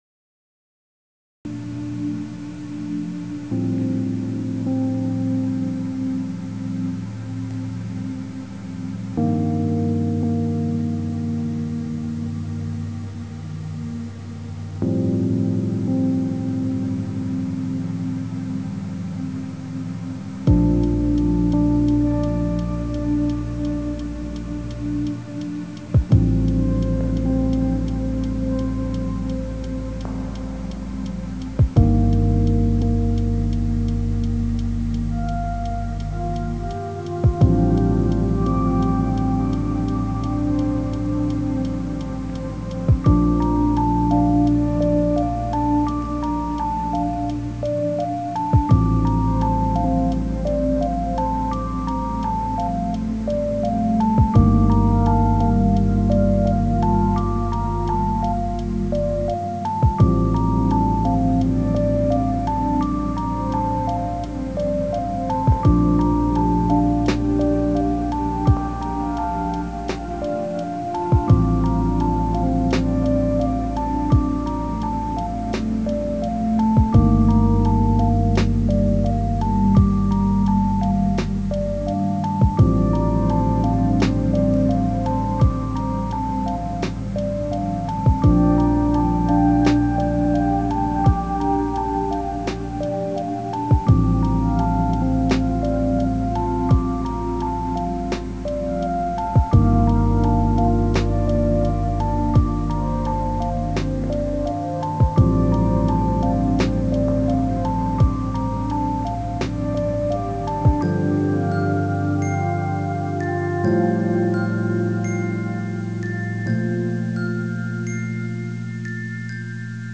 3:12 · Calm Ambient